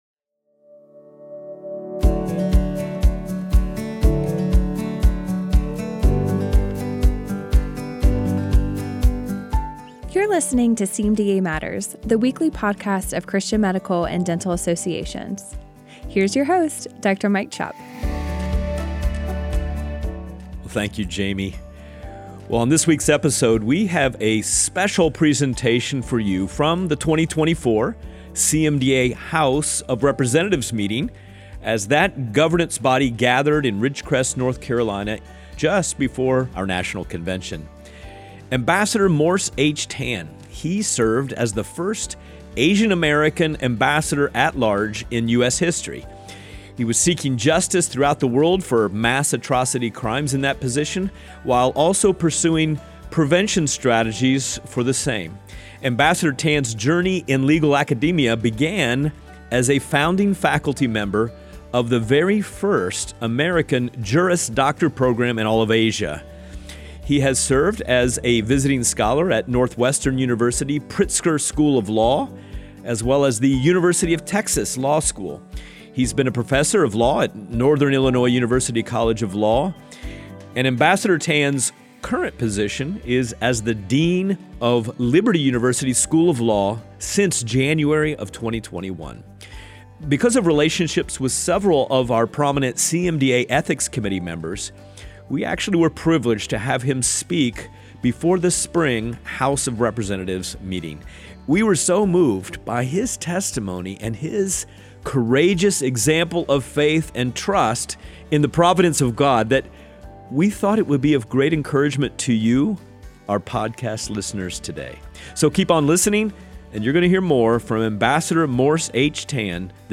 On this week’s episode of CMDA Matters, we share a special recording with Ambassador Morse Tan from the 2024 CMDA National Convention, as he shares about his journey to walk in Christ’s footsteps through his work to advance justice and protect vulnerable people in our world.